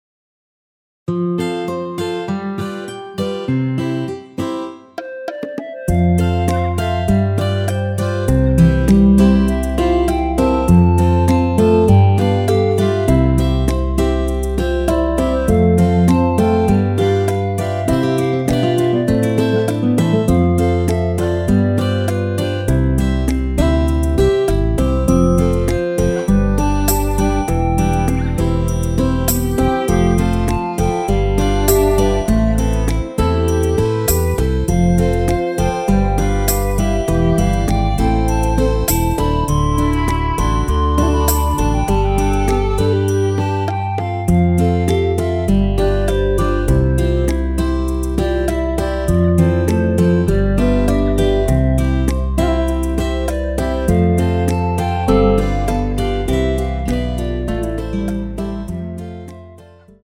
시작 부분 여자 파트 삭제, 바로 남자 파트로 시작 됩니다.(가사 참조)
원키에서(+6)올린 멜로디 포함된 시작 부분 여자파트 삭제 제작된 MR입니다.(미리듣기 참조)
앞부분30초, 뒷부분30초씩 편집해서 올려 드리고 있습니다.
(멜로디 MR)은 가이드 멜로디가 포함된 MR 입니다.